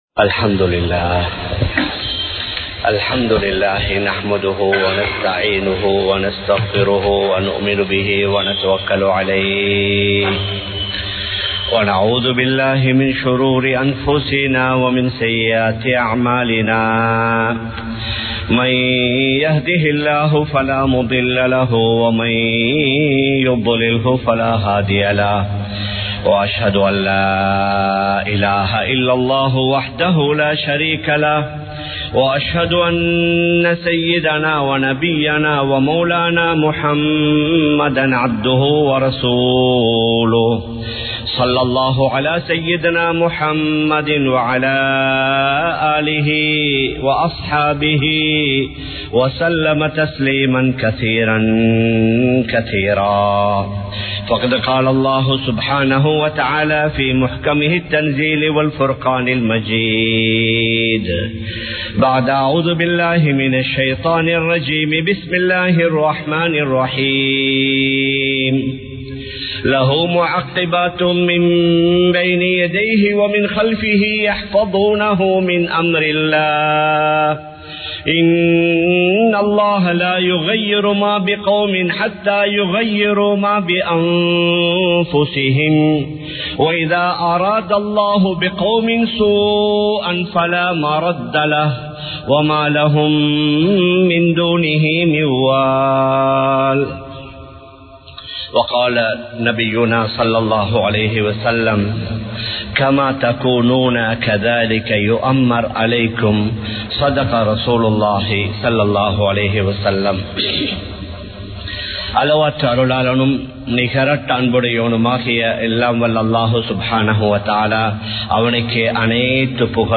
அல்லாஹ்வுக்கு நன்றி செலுத்துவோம் | Audio Bayans | All Ceylon Muslim Youth Community | Addalaichenai
Kollupitty Jumua Masjith